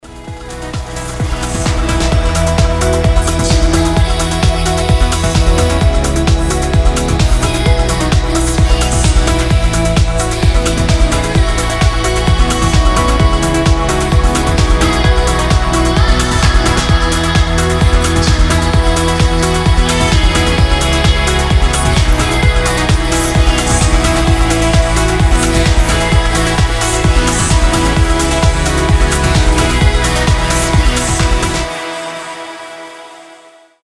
Progressive melodic house